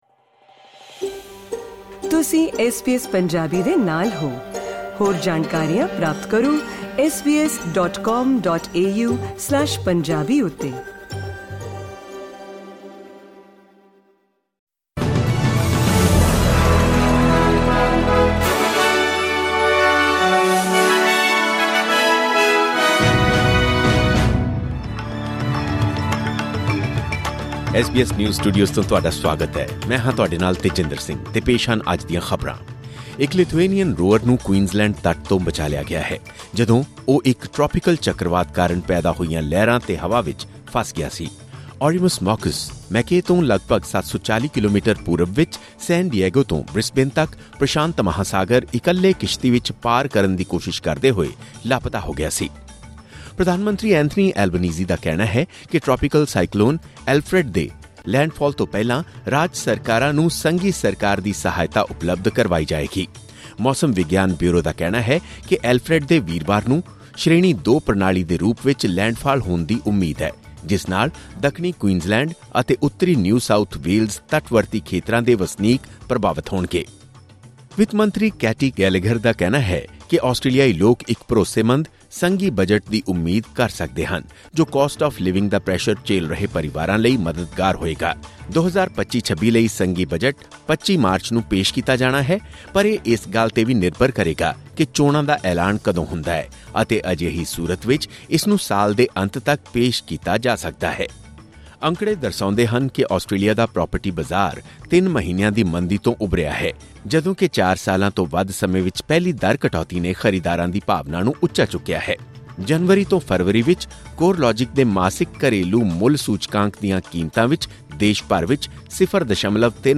ਖਬਰਨਾਮਾ: ਸਾਈਕਲੋਨ ਅਲਫ੍ਰੇਡ ਦੇ ਲੈਂਡਫਾਲ ਤੋਂ ਪਹਿਲਾਂ ਰਾਜ ਸਰਕਾਰਾਂ ਨੂੰ ਸਹਾਇਤਾ ਉਪਲਬਧ ਕਰਵਾਈ ਜਾਵੇਗੀ: ਐਂਥਨੀ ਅਲਬਾਨੀਜ਼ੀ